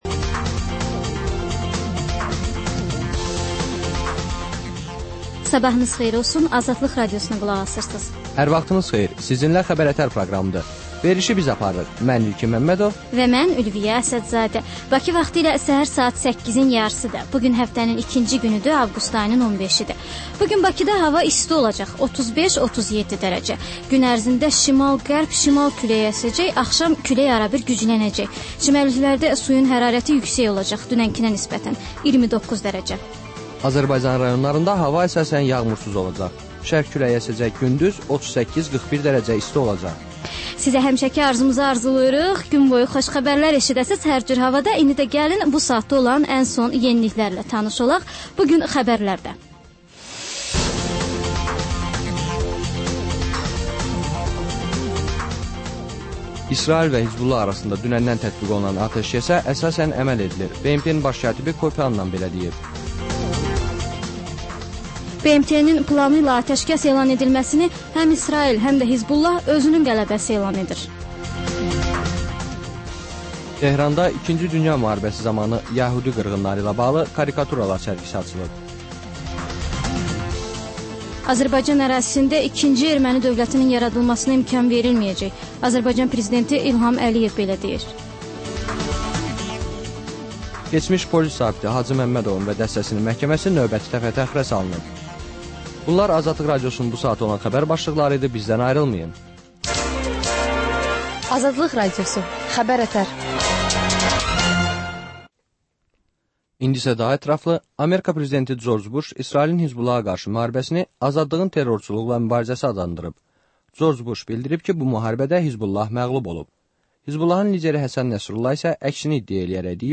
Səhər-səhər, Xəbər-ətərI Xəbər, reportaj, müsahibə